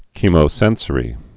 (kēmō-sĕnsə-rē, kĕmō-)